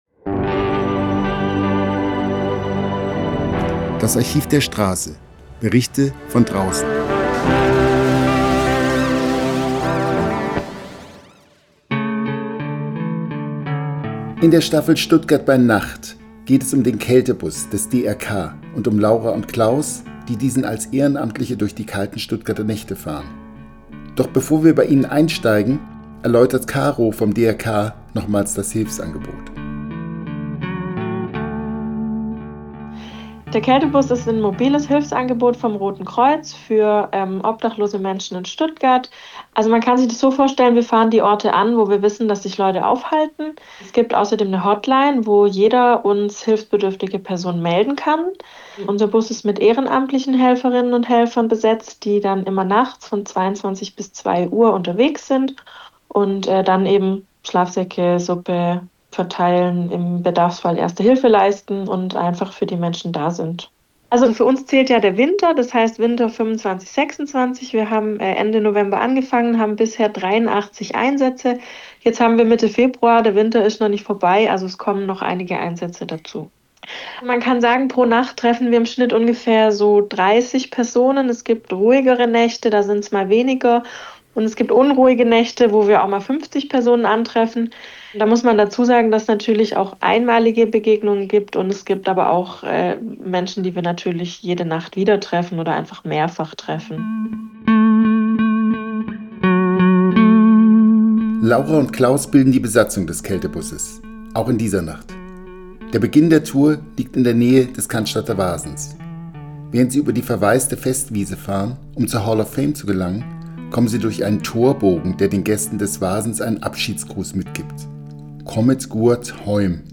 Beschreibung vor 4 Tagen In dieser Folge steuert der Kältebus 3 typische Orte an, an denen nachts die Menschen ohne Wohnung anzutreffen sind: Die Hall of Fame, ein Bankautomaten Foyer, und der Rosensteinpark.